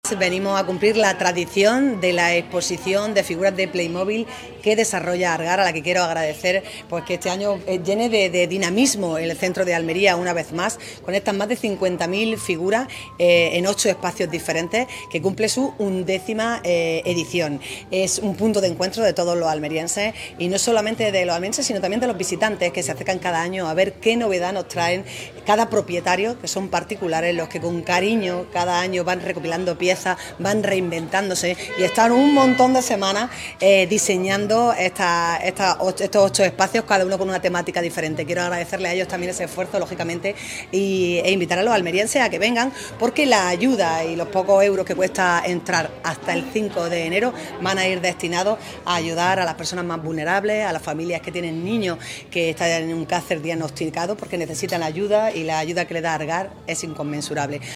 ALCALDESA-EXPOSICION-PALYMOBIL-ARGAR.mp3